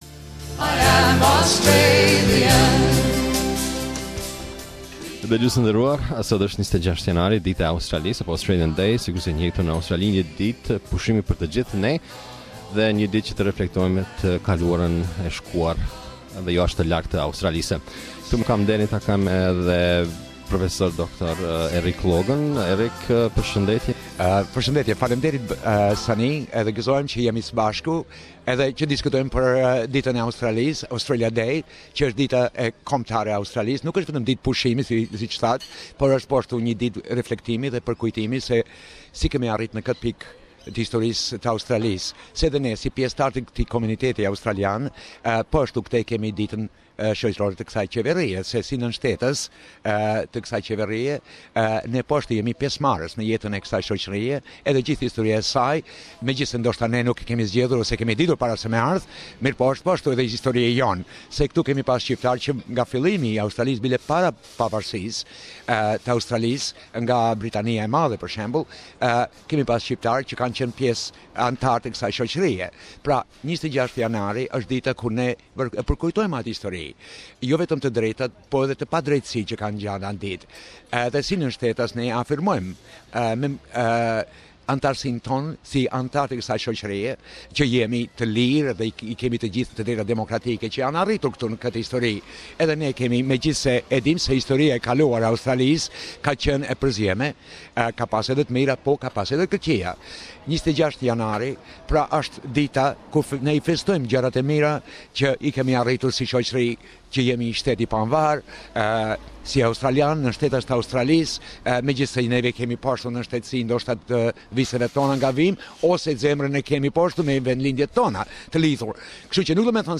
Australia Day - Interview